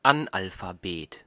Ääntäminen
Synonyymit Dilettant Ääntäminen Haettu sana löytyi näillä lähdekielillä: saksa Käännös Ääninäyte Substantiivit 1. illiterate US 2. analphabet Artikkeli: der .